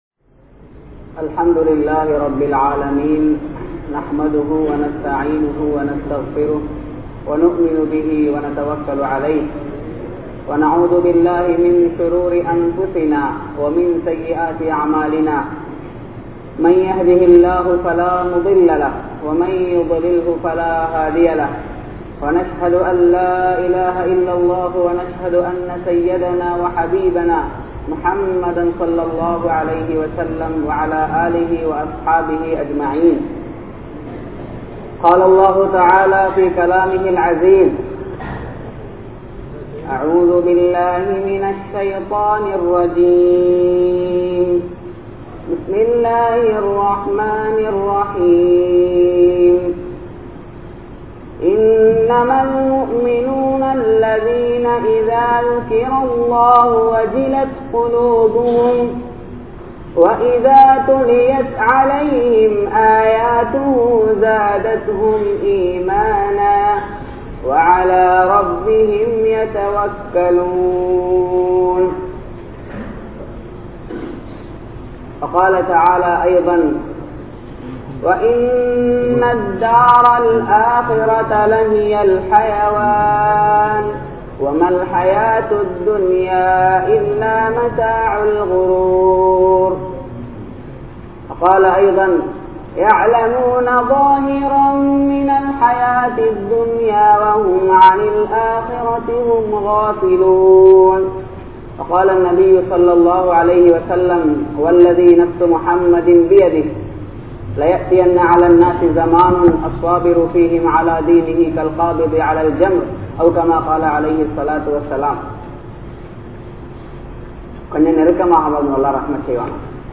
Ganniyamaana Vaalkai Veanduma? (கண்ணியமான வாழ்க்கை வேண்டுமா?) | Audio Bayans | All Ceylon Muslim Youth Community | Addalaichenai